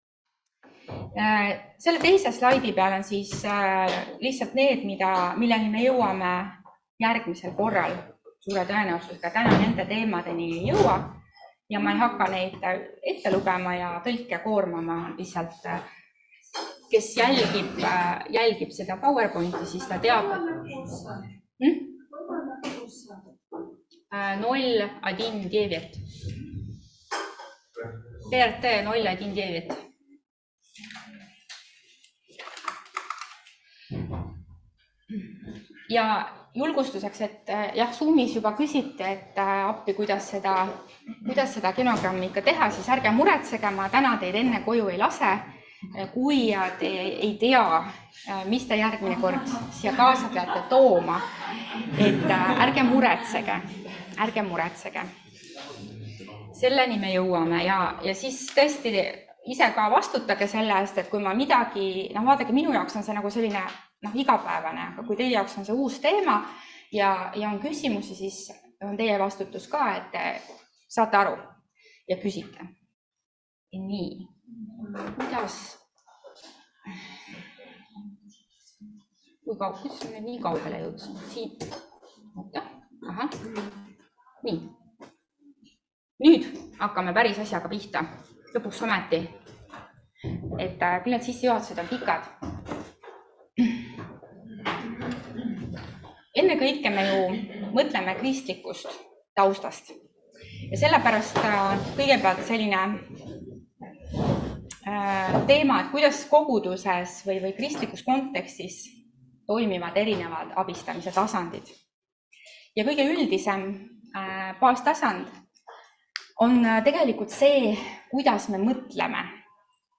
Perenõustamine. 2. loeng [ET] – EMKTS õppevaramu